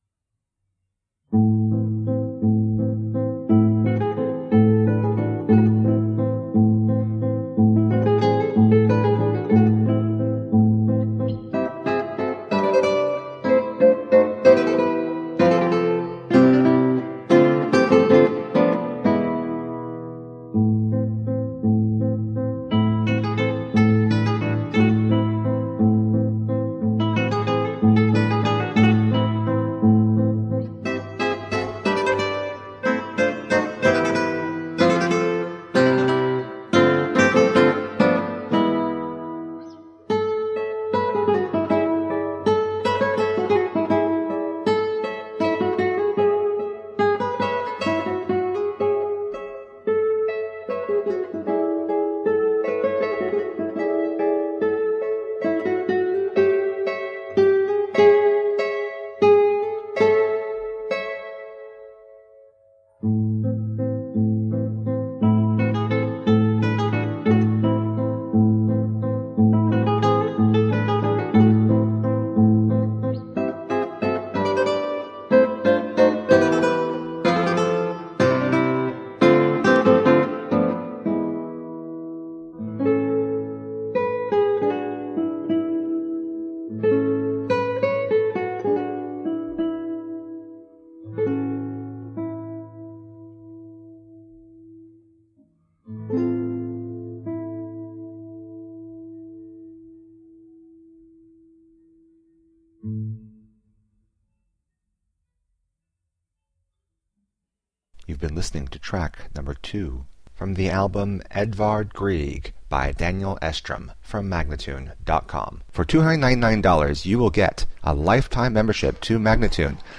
Instrumental
Classical Guitar